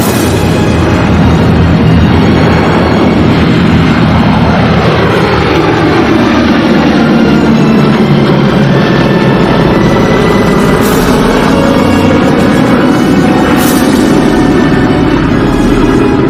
Play, download and share Dramatic Airplane Louder original sound button!!!!
dramatic-airplane-louder.mp3